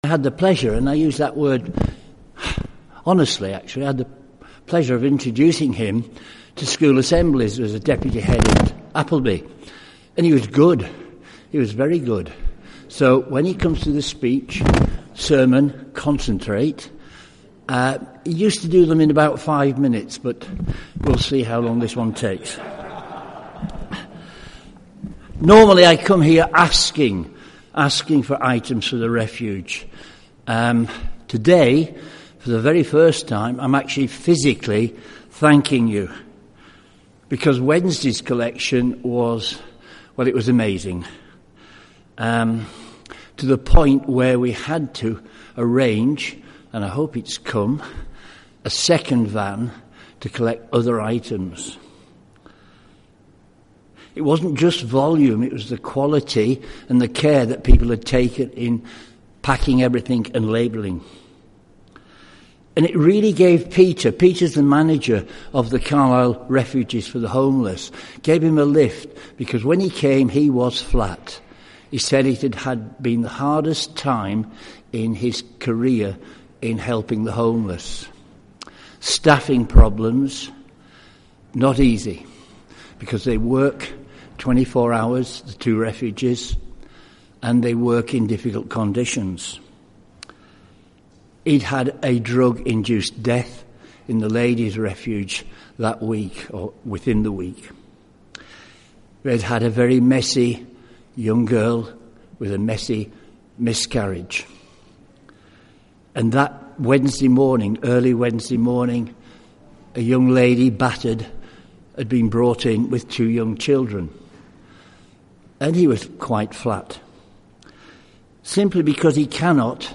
A message from the service
From Service: "10.30am Service"